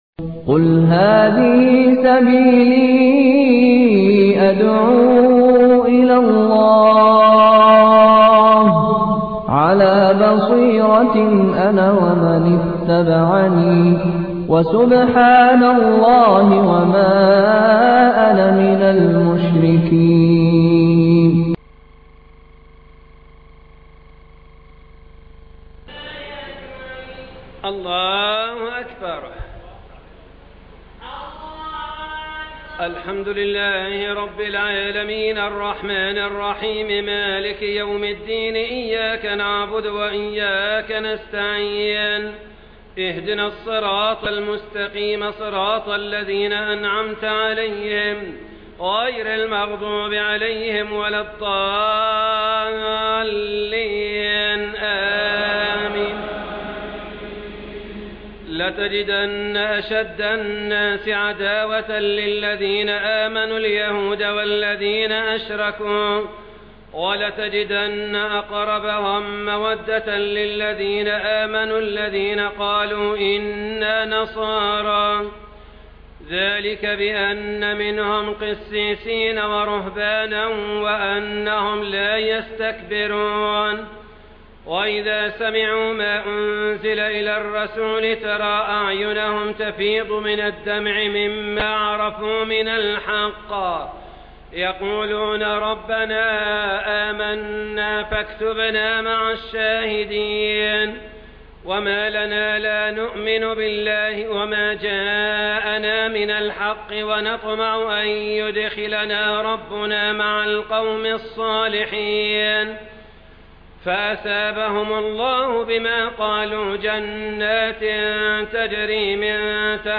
صلاة التراويح من المسجد الأقصى (ليلة 6 رمضان) 1432 هـ - قسم المنوعات